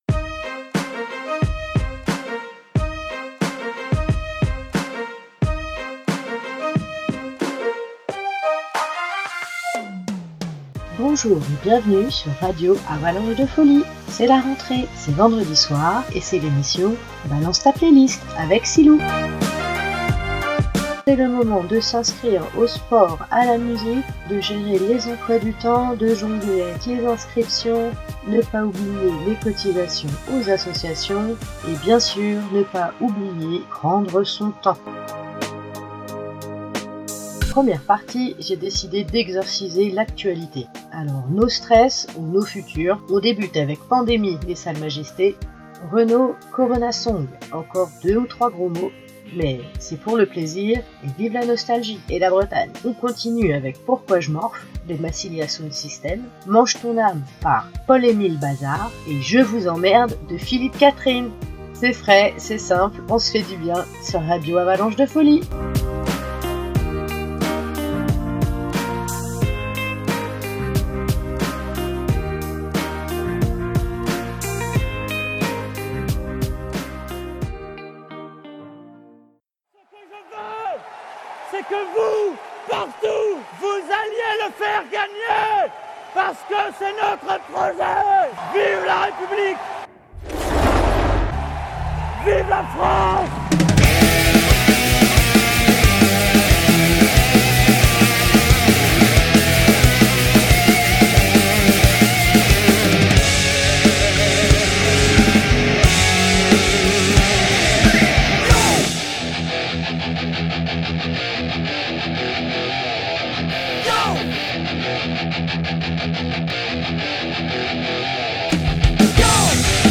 Genre : MUSIQUE.